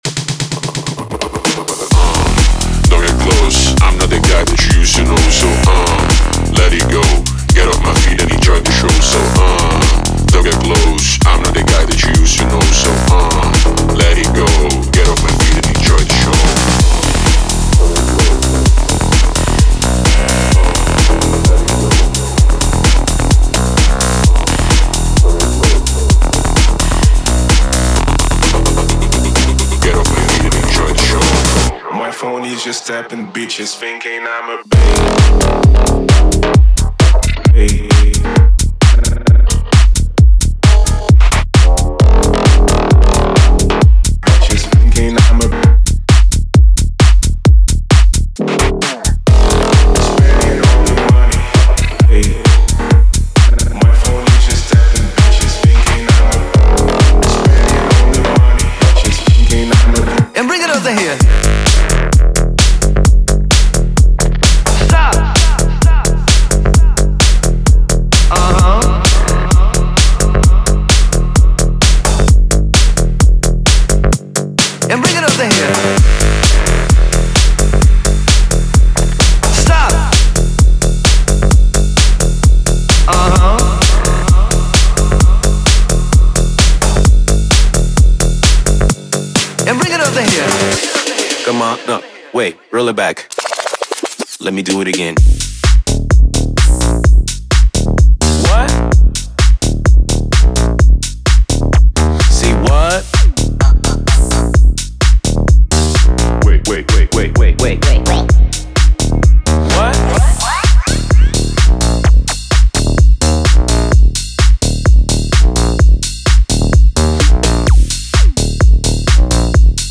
If you’re looking for tight grooves, clean, punchy bass, and a sound aesthetic that works on the dancefloor, this pack is for you.
Powerful sounds designed from scratch to give your tracks that standout energy.
Clean, punchy samples to create your own unique grooves: